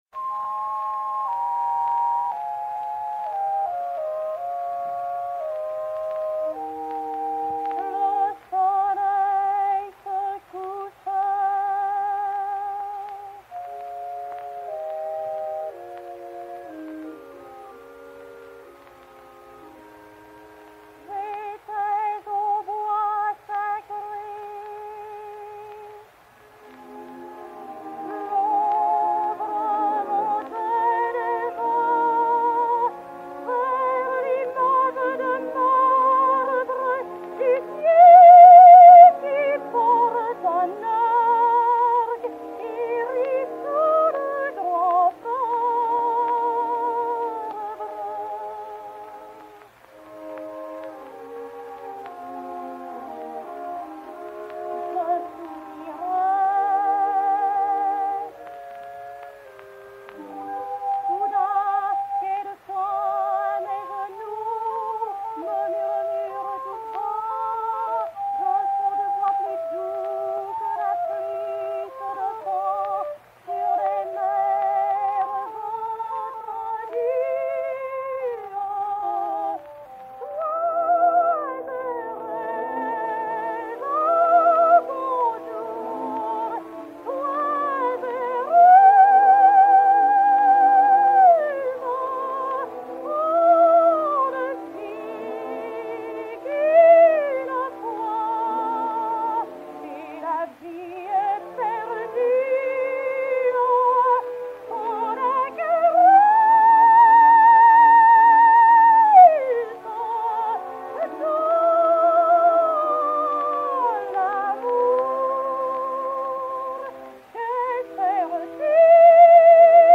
et Orchestre